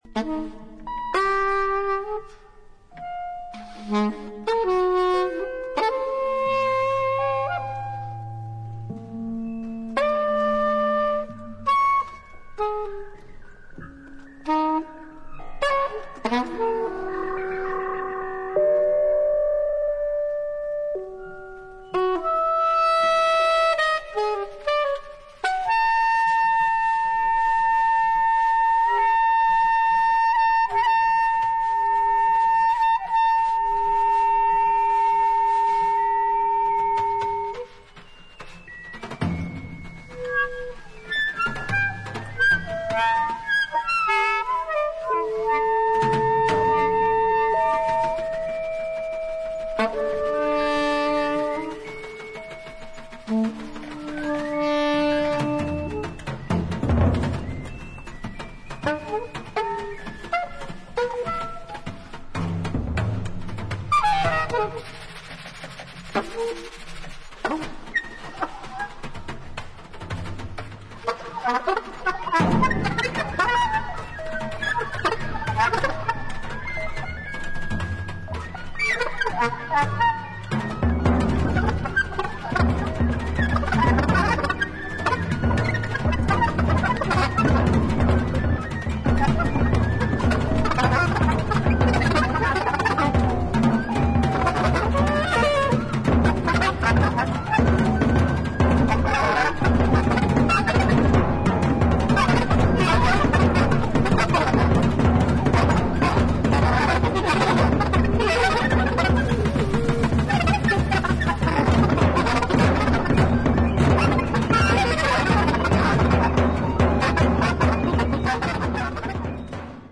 1977年12月にイタリア/ピストイアで行ったライヴを収録したCDアルバム